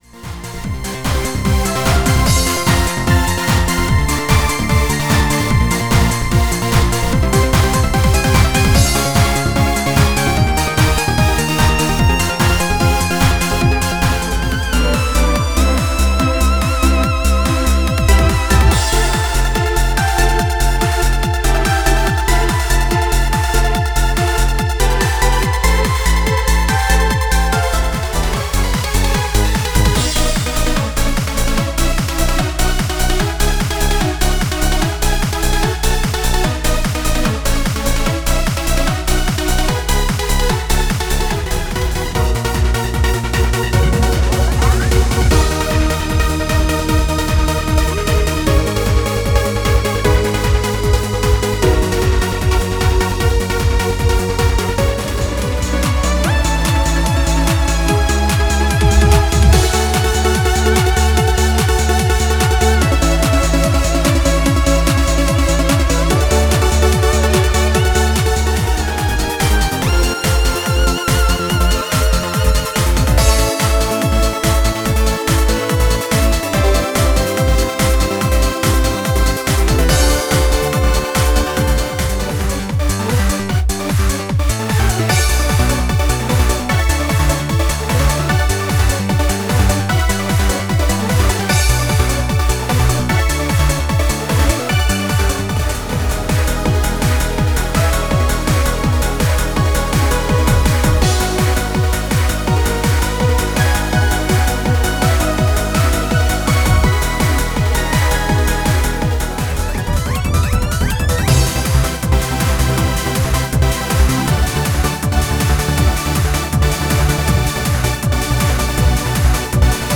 こちらですが、過去に発表しました曲を再マスタリングし、CD 音質を越える 48kHz 24bit で収録しております。
クロスフェードデモの .flac ファイルです。実際の音質はこちらと同じものになります。